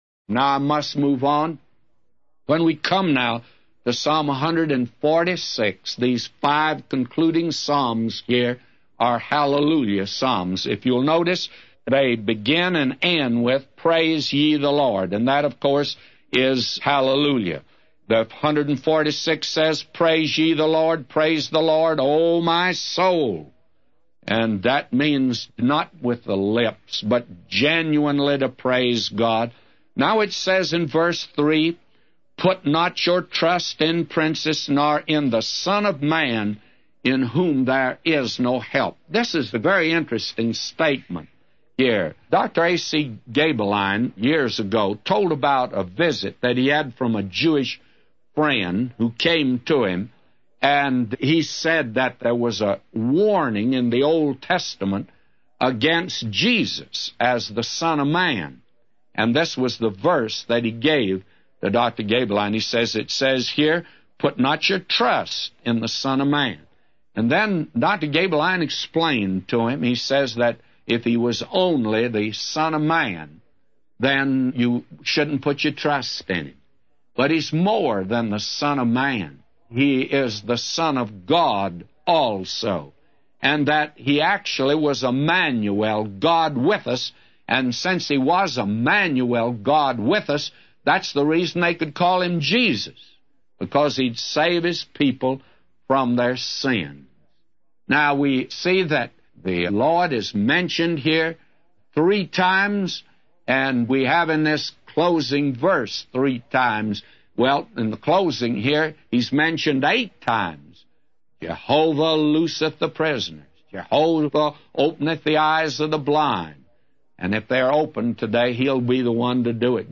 A Commentary By J Vernon MCgee For Psalms 146:1-999